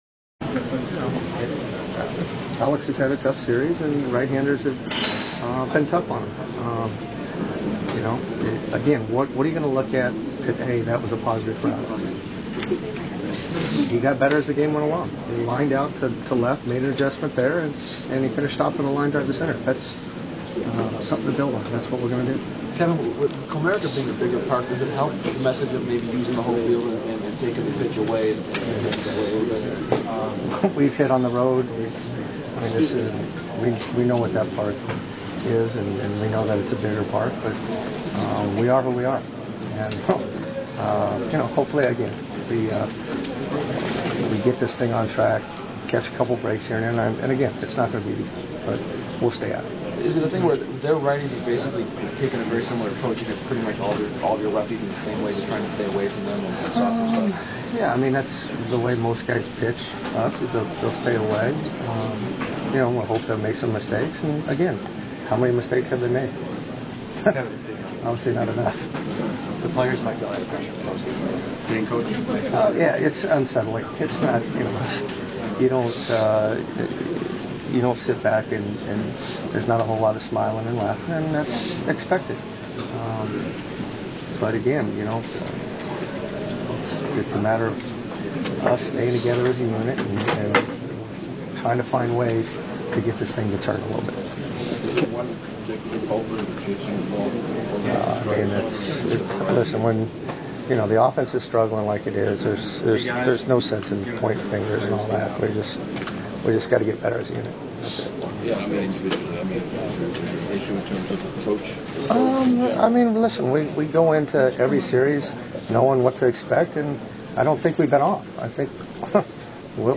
Hitting Coach Kevin Long Clubhouse Audio